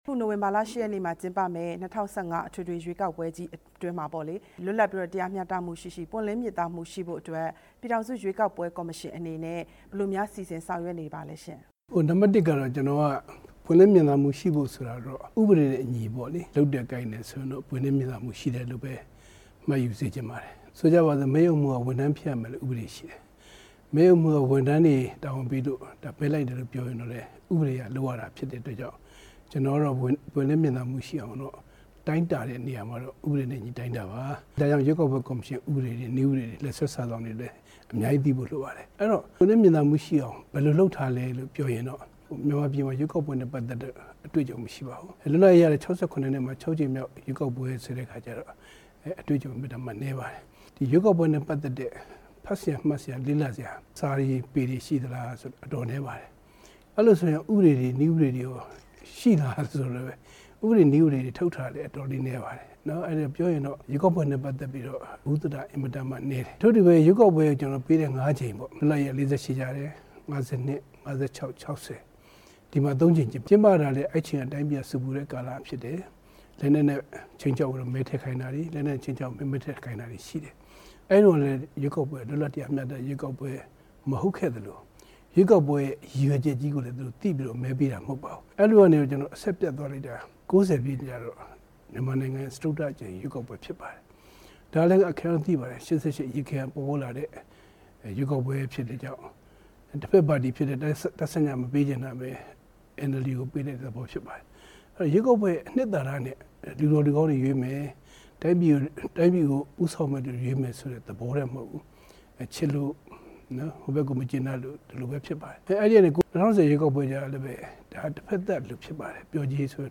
နေပြည်တော်က ပြည်ထောင်စု ရွေးကောက်ပွဲကော်မရှင် ရုံးချုပ်မှာ RFA နဲ့ သီးသန့်မေးမြန်းရာမှာ ကော်မရှင်ဥက္ကဌ ဦးတင်အေးက ပြောလိုက်တာဖြစ်ပါတယ်။